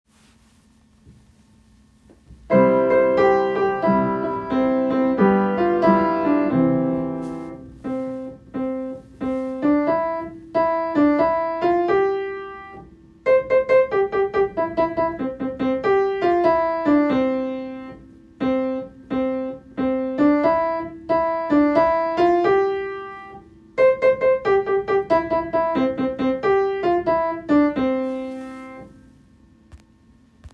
Audition Material (Ages 5-10)
Row-Row-Row-Your-Boat-Melody.m4a